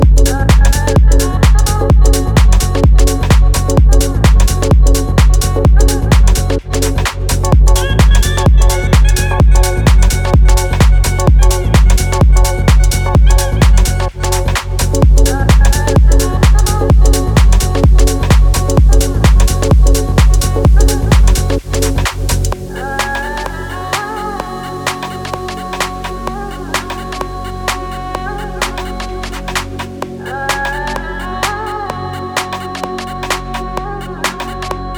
Dance Electronic Ambient
Жанр: Танцевальные / Электроника